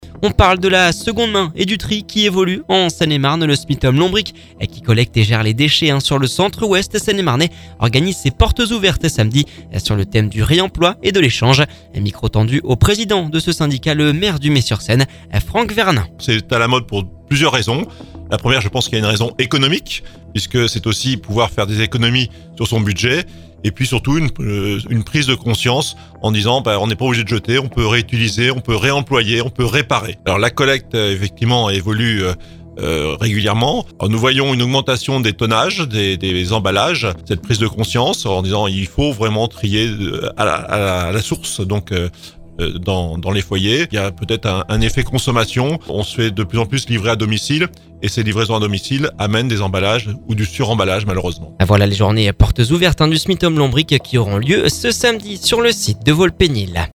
Micro tendu au président de ce syndicat, le maire du Mée sur Seine Franck Vernin.